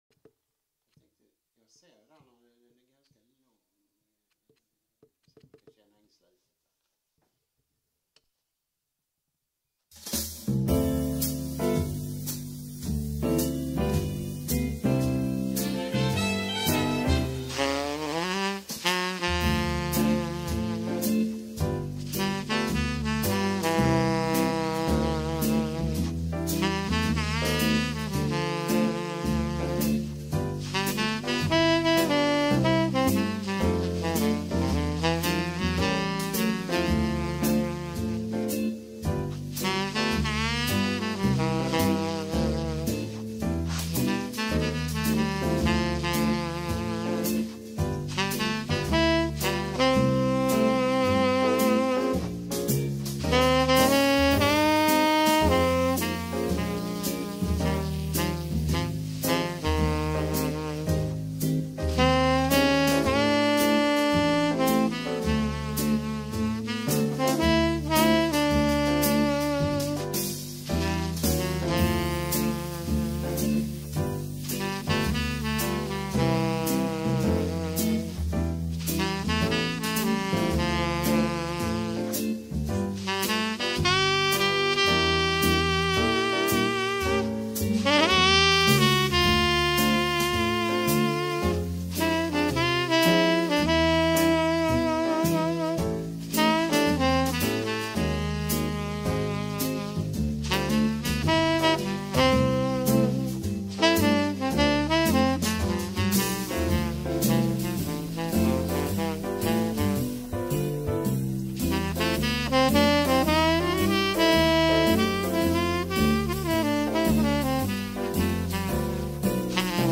Saxofonunderhållning